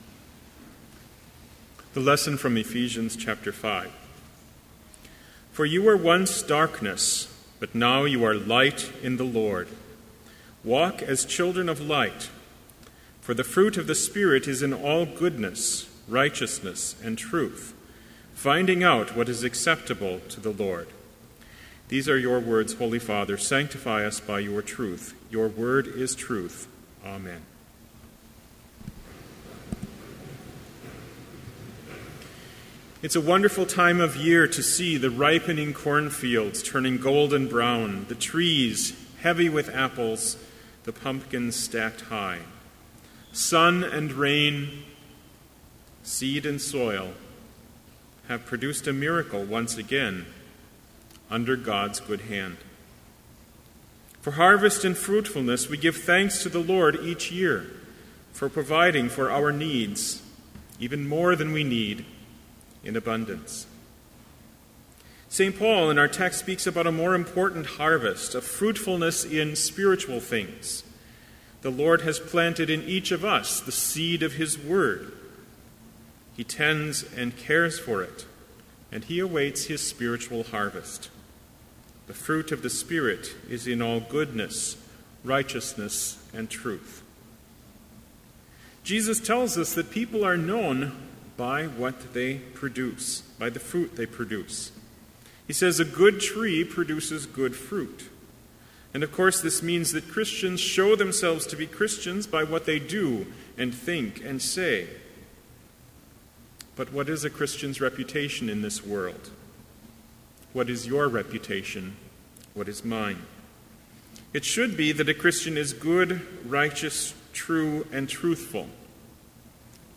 Complete Service
• Hymn 511, vv. 1-4, Lord Jesus Christ, with Us Abide
• Homily
This Chapel Service was held in Trinity Chapel at Bethany Lutheran College on Tuesday, October 14, 2014, at 10 a.m. Page and hymn numbers are from the Evangelical Lutheran Hymnary.